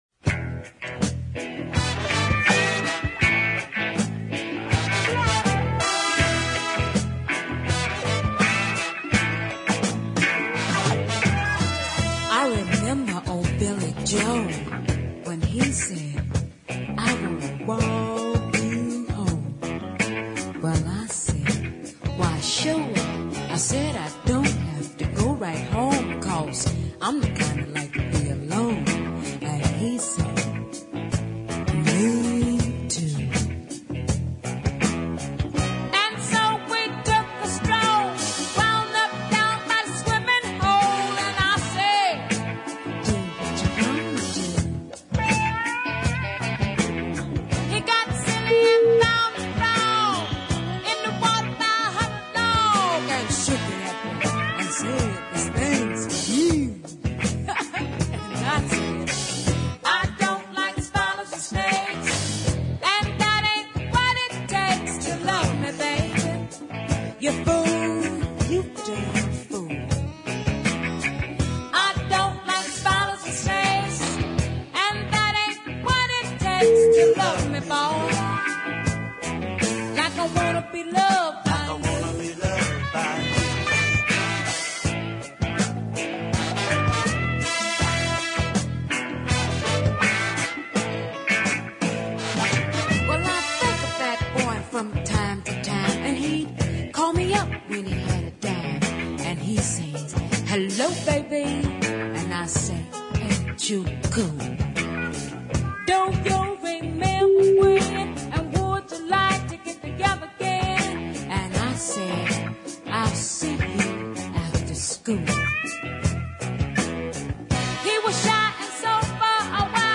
slow strutting southern funk
love the horns
the sultry almost whispering tone